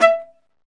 Index of /90_sSampleCDs/Roland L-CD702/VOL-1/STR_Viola Solo/STR_Vla2 _ marc